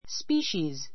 species spíːʃiːz ス ピ ーシー ズ 名詞 複 species （生物学上の） 種 しゅ ⦣ 動植物の分類上の単位で, お互 たが いの交配が可能なグループ.